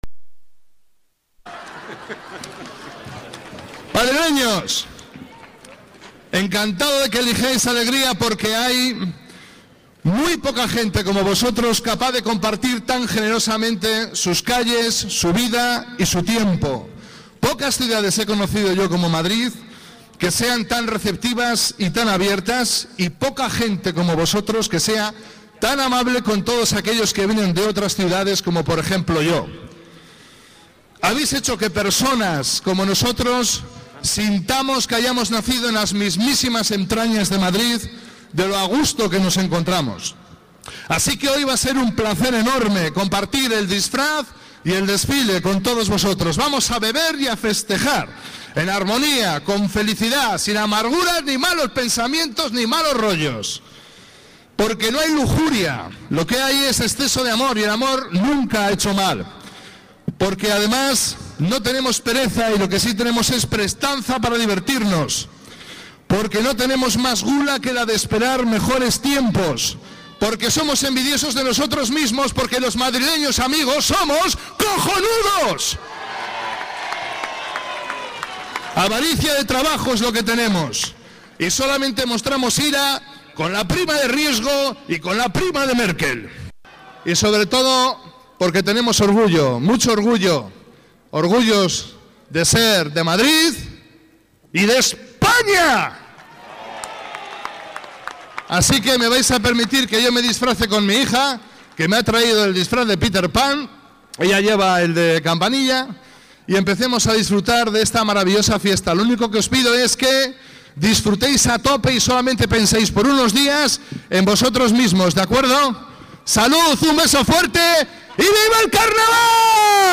El presentador Carlos Sobera ha dado el pistoletazo de salida al Carnaval 2013, con un pregón en el que ha elogiado la hospitalidad y buen talante de los madrileños, y en el que ha invitado a
Nueva ventana:Pregón
CarlosSoberaPregonCarnaval-09-02.mp3